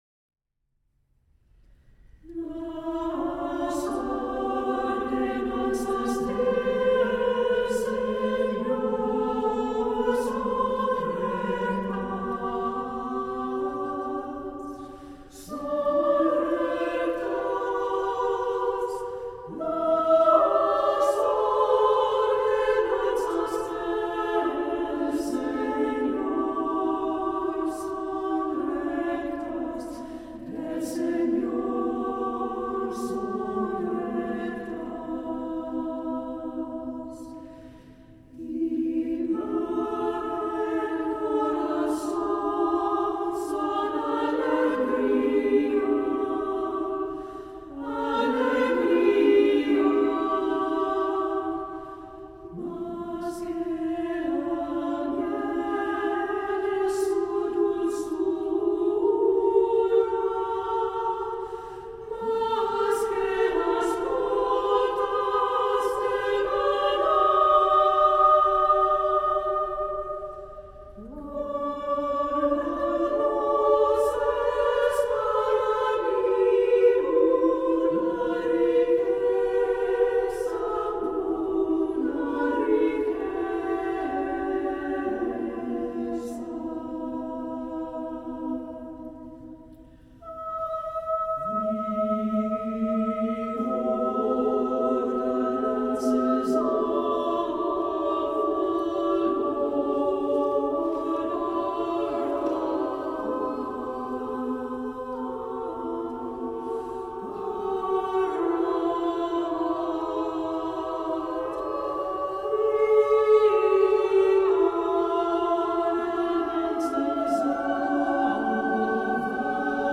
SSA a cappella